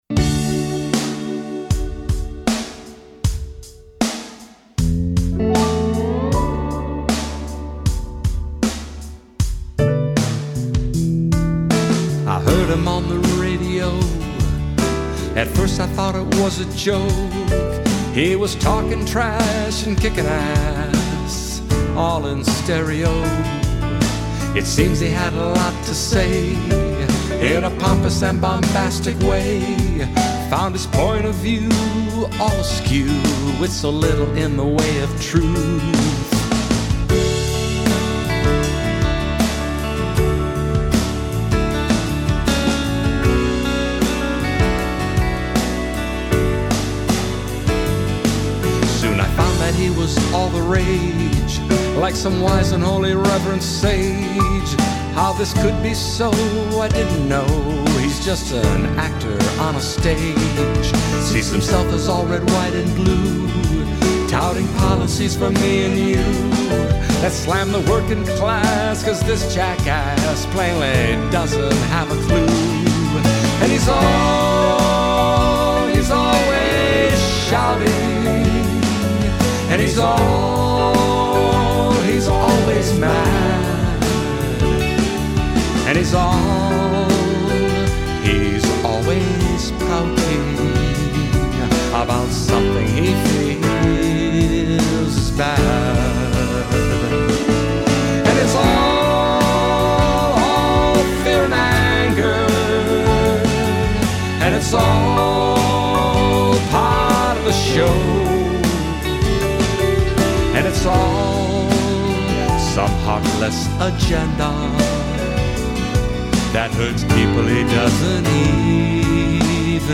lead and back up vocals
Rhythm guitar